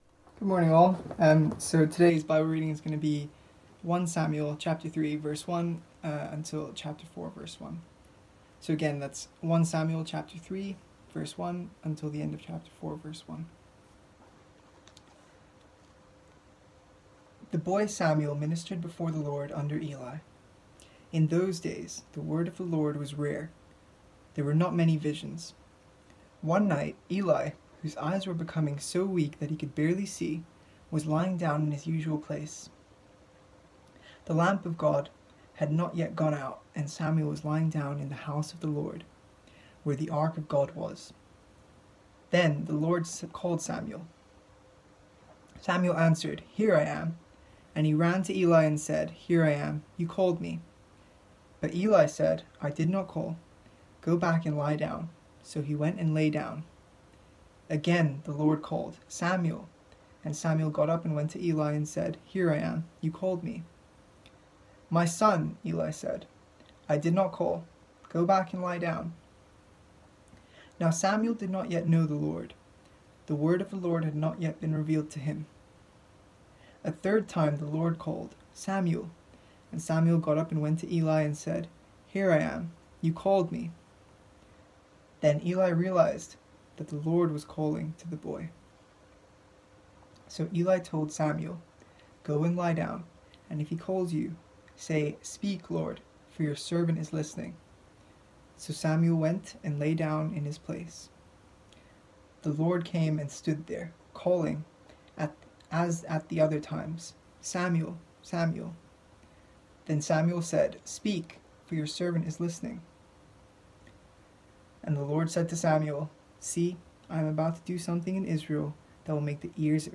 This was a one-off sermon focussing on 1 Samuel 3:1-4:1 and had this outline: